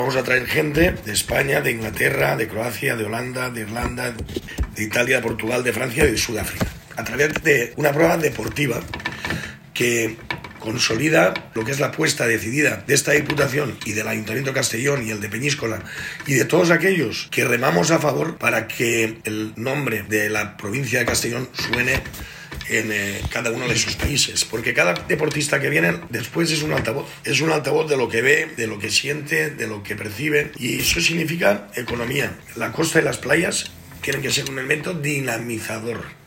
Diputado-Andres-Martinez-presentacion-de-los-campeonatos-de-Pesca-Deportiva.mp3